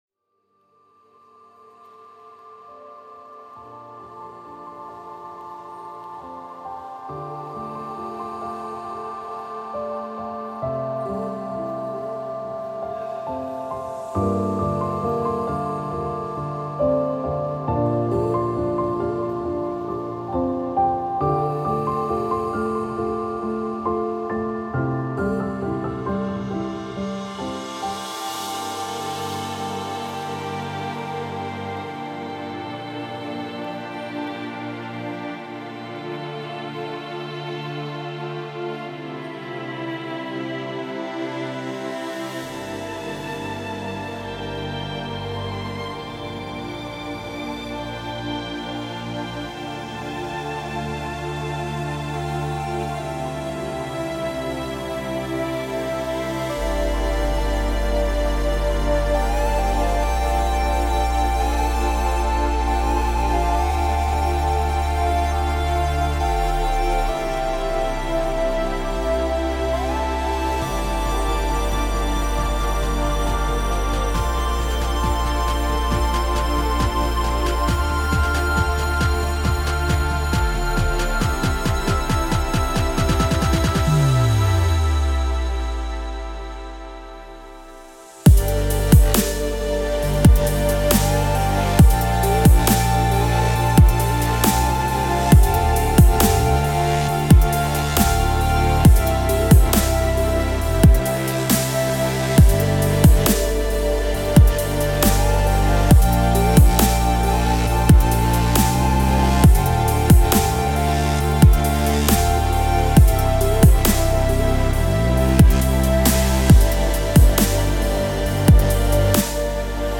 это трек в жанре поп-рок